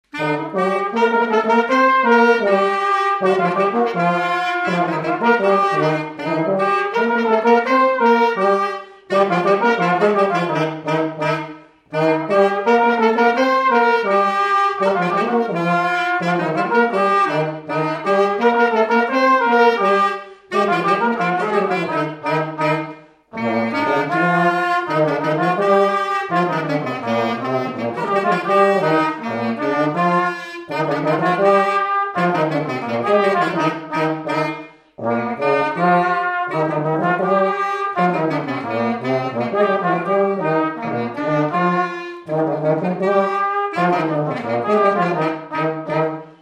Chants brefs - A danser
Résumé instrumental
danse : scottish (autres)
Enquête Arexcpo en Vendée
Pièce musicale inédite